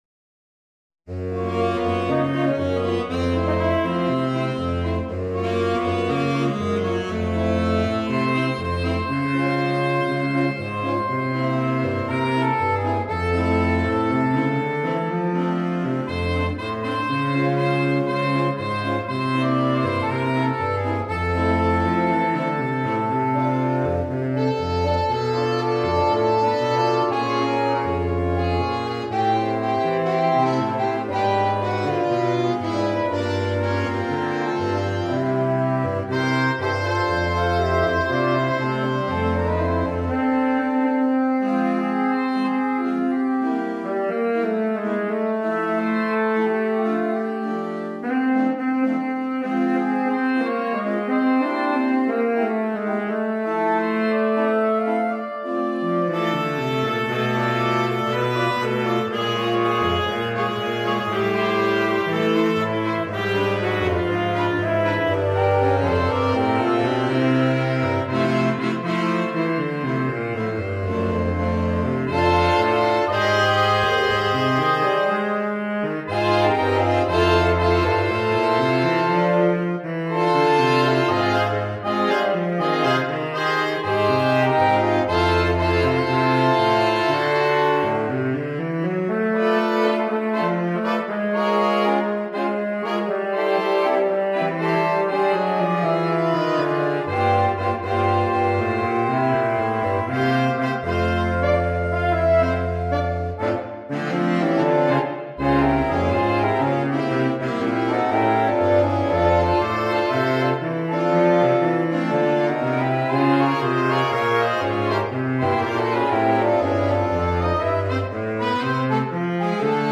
for Saxophone Quintet SATTB or AATTB